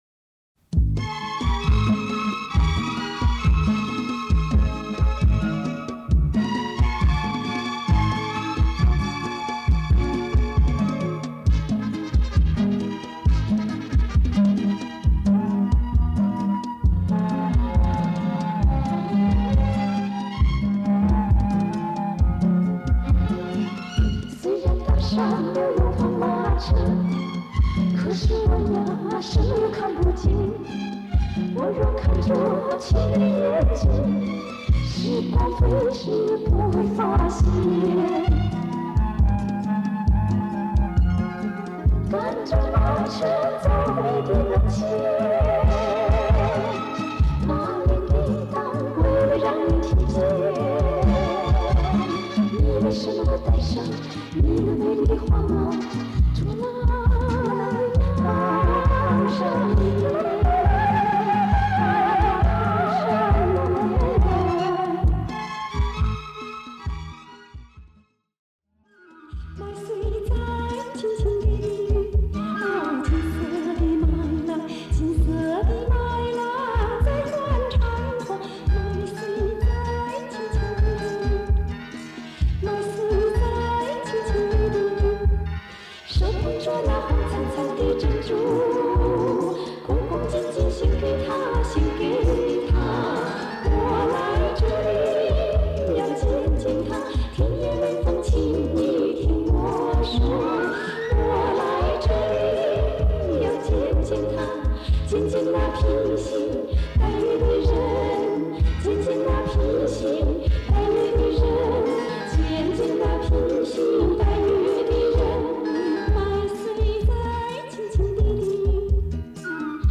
女声
音频质量一般，好多年前从网上拿的。